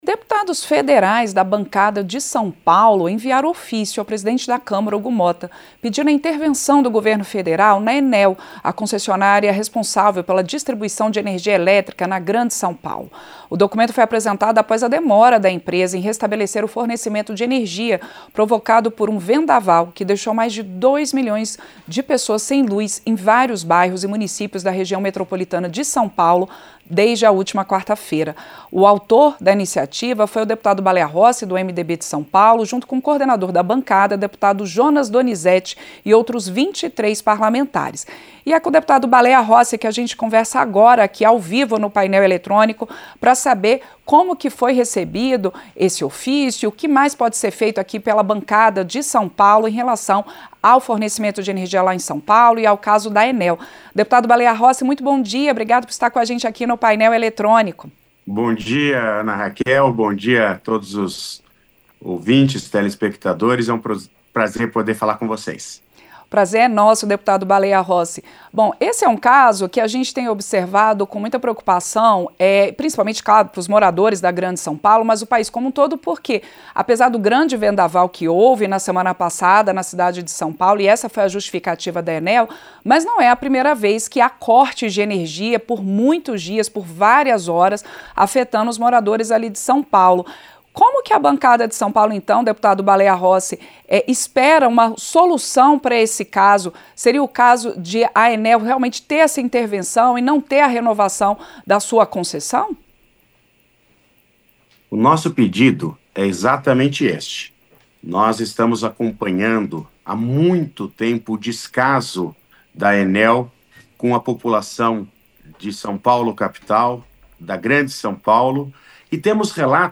Entrevista – Dep. Baleia Rossi (MDB-SP)